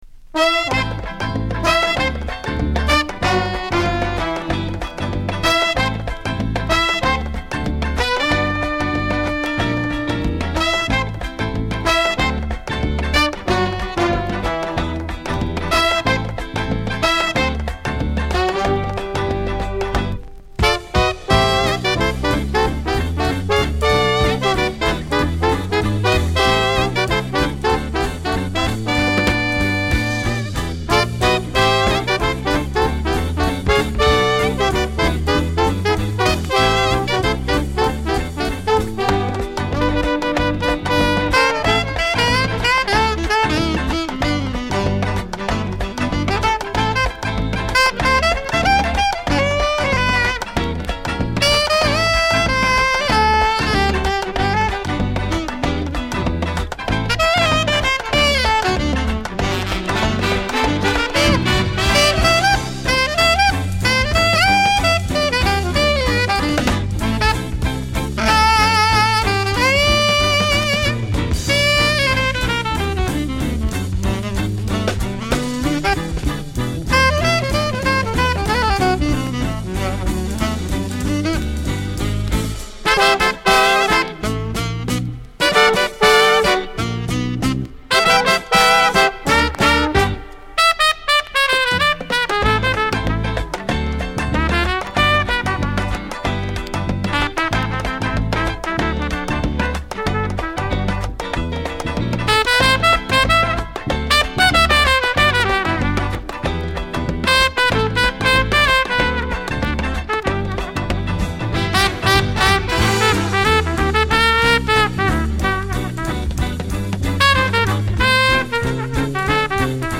1枚目がスタジオ録音、2枚目がライブ録音盤になっています。